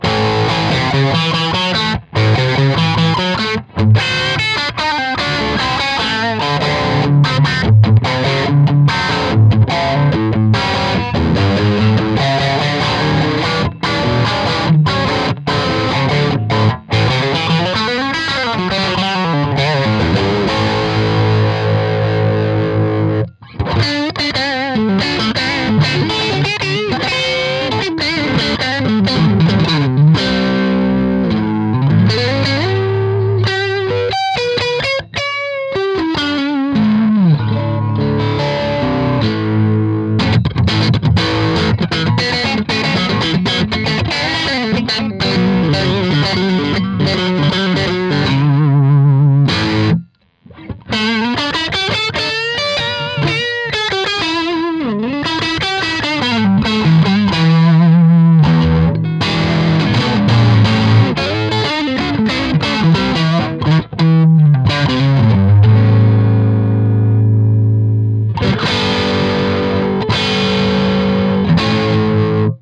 telecaster_fralin_blues_special.mp3